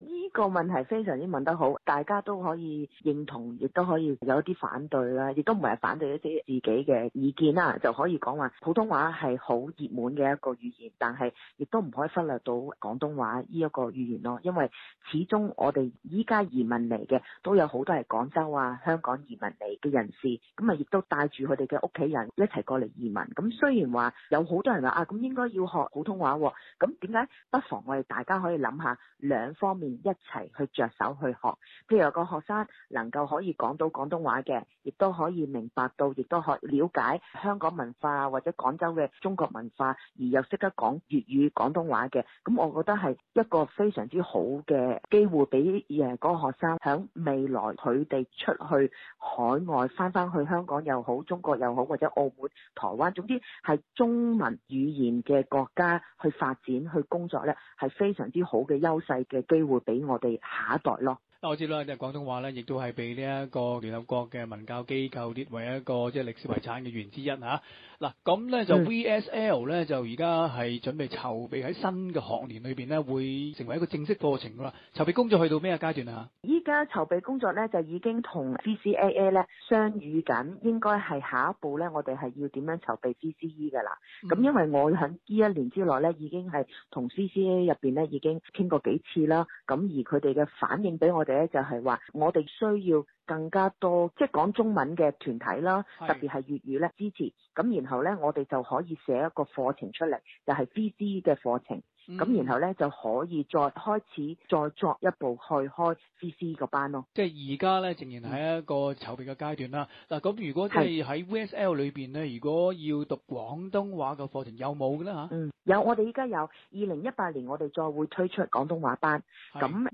【社團專訪】VSL有意推出廣東話會考文憑課程